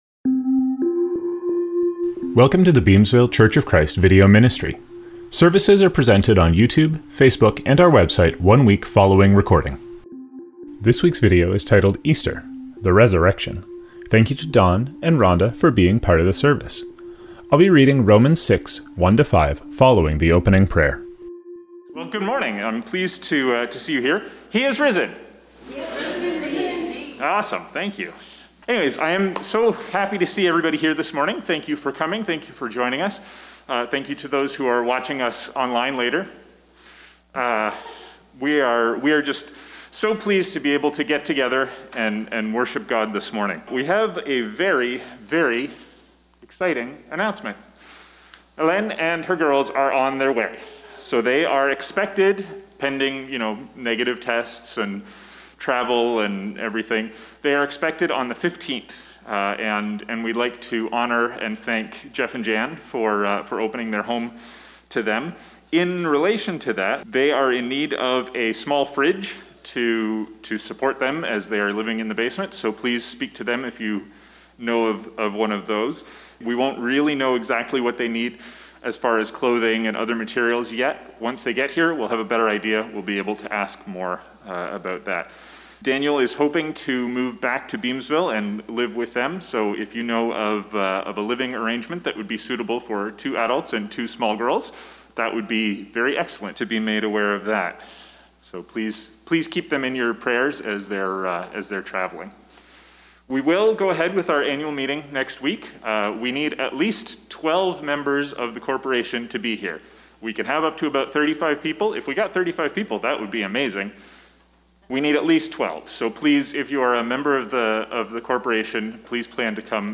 Songs & videos from this service
Sermon